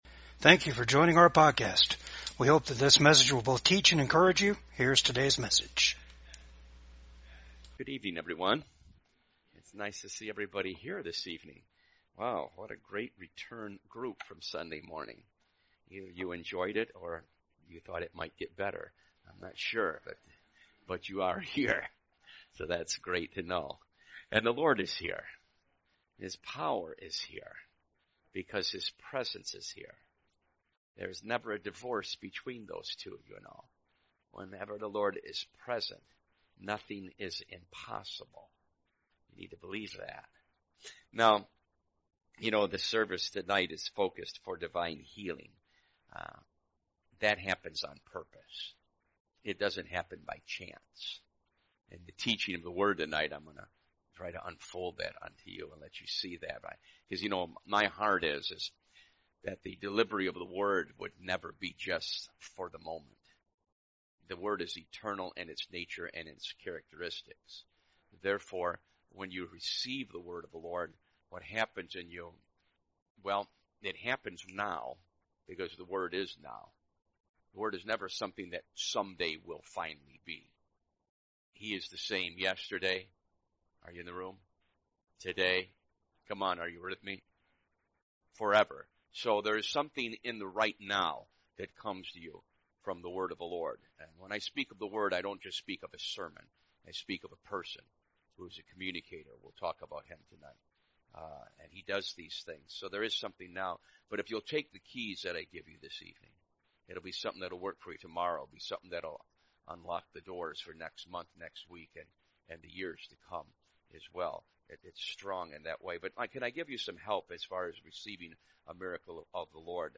Service Type: REFRESH SERVICE